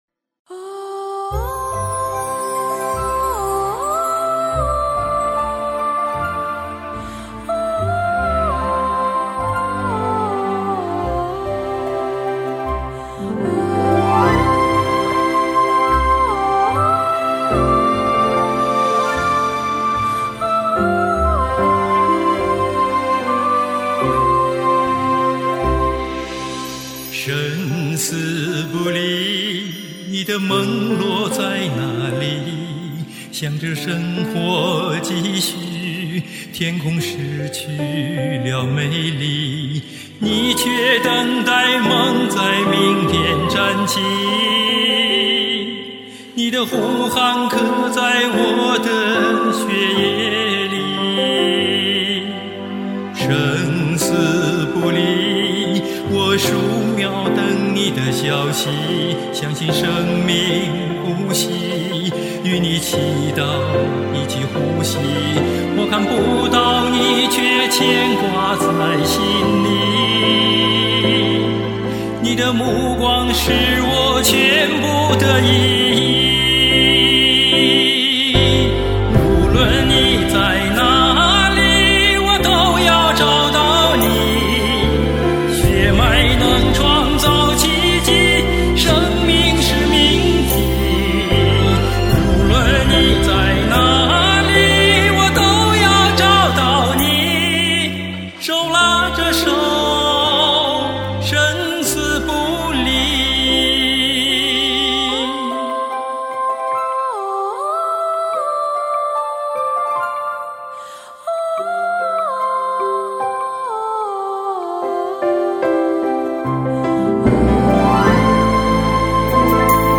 很感人，感情的释放很到位
但是现在找不到好的伴奏，都是消音版的。质量很差。